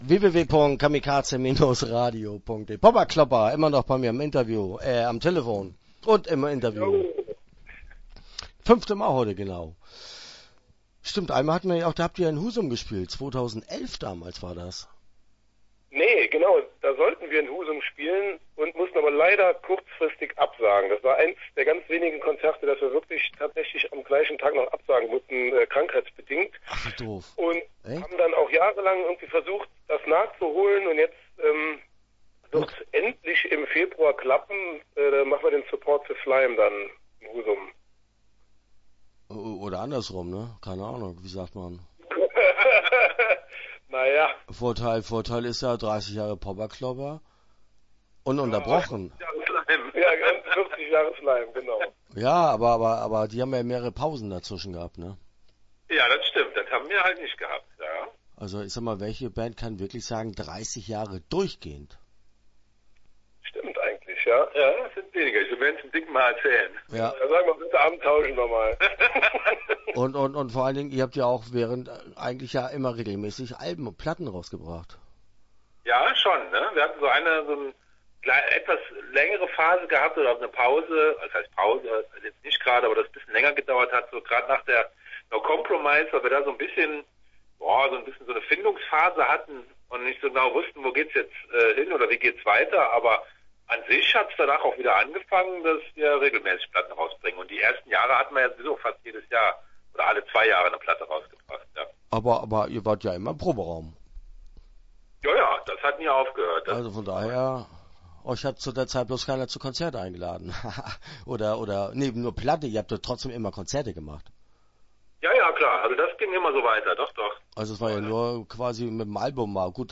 Popperklopper - Interview Teil 1 (9:45)